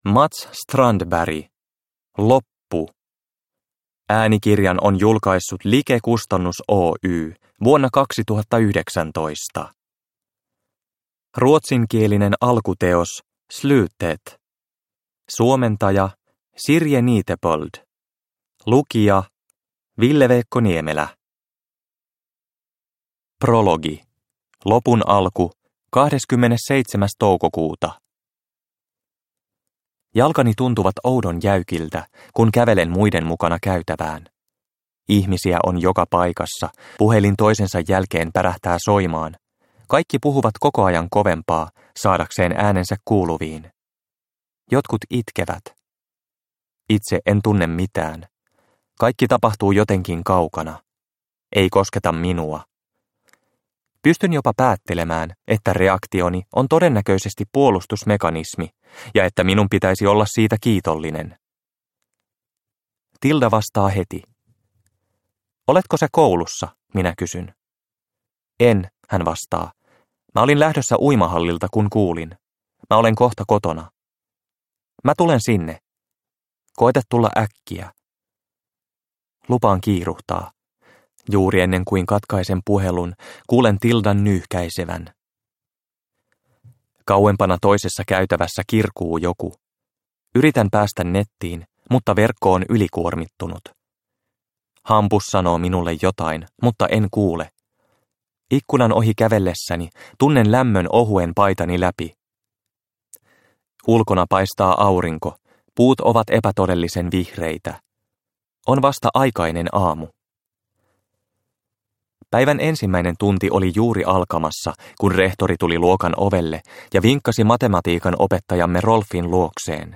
Loppu – Ljudbok – Laddas ner